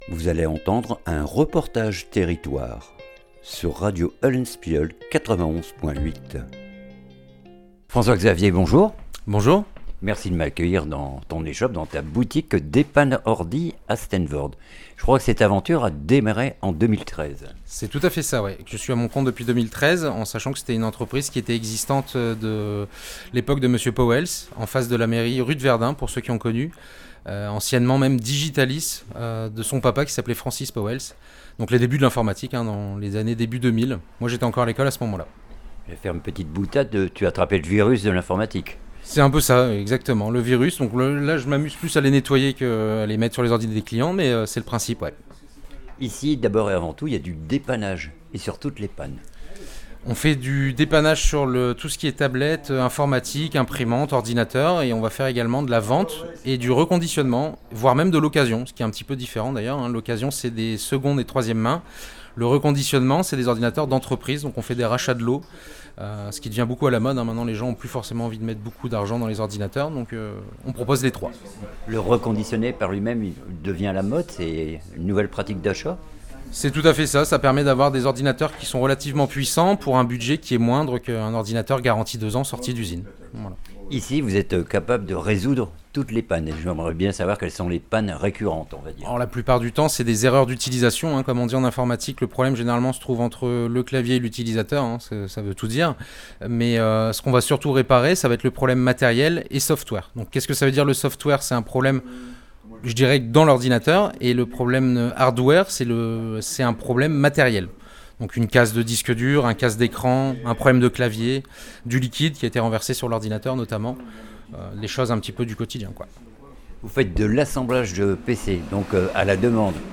REPORTAGE TERRITOIRE DEPAN' ORDI STEENVOORDE